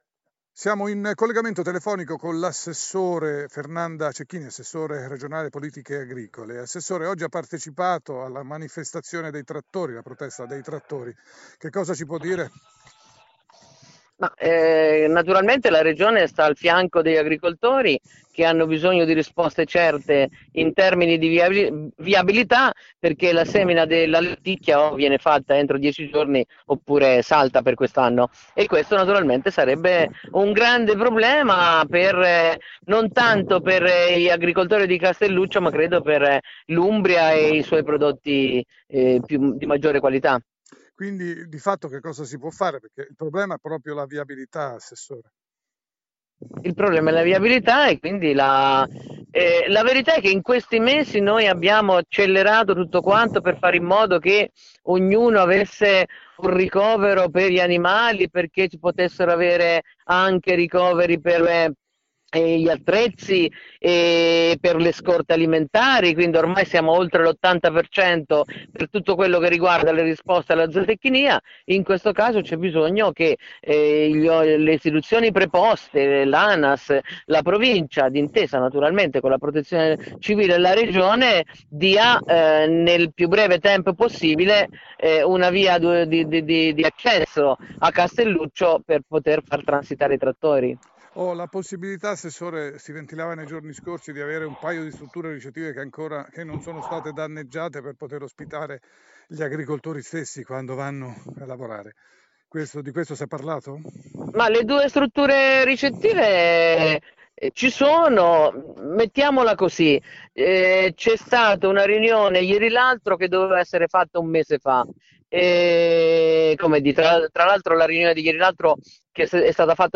Semina lenticchia, protesta agricoltori a Norcia, regione vicina a coltivatori [INTERVISTA ASSESSORE CECCHINI]
intervista-assesore-cechini.mp3